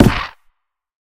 Minecraft Version Minecraft Version 1.21.5 Latest Release | Latest Snapshot 1.21.5 / assets / minecraft / sounds / block / creaking_heart / hit / creaking_heart_hit3.ogg Compare With Compare With Latest Release | Latest Snapshot
creaking_heart_hit3.ogg